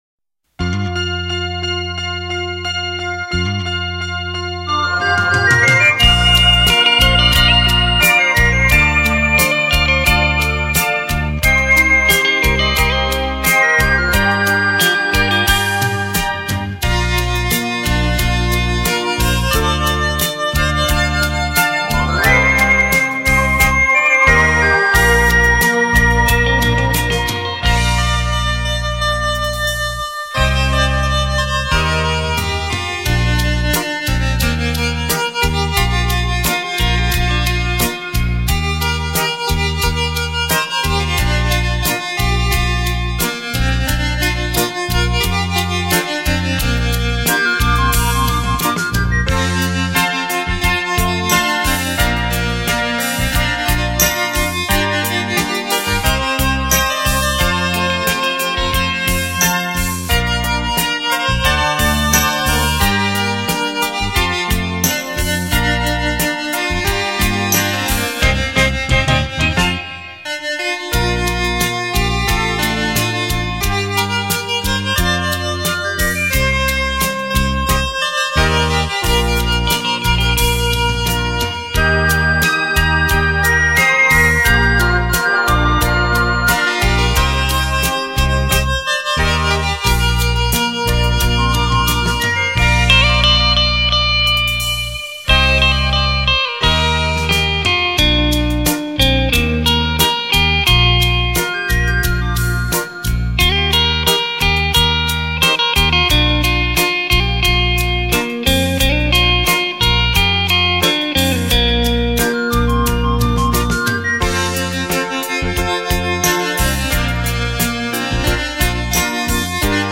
耳熟能详的曲目将在电声乐器的优美丰富韵律下带你走入一个全新的音乐世界。